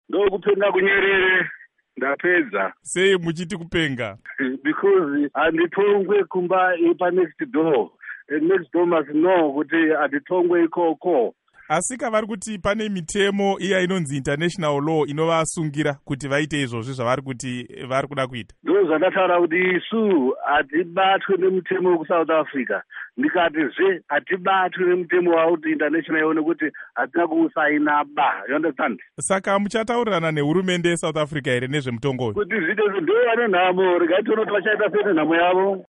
Hurukuro naVaJohannes Tomana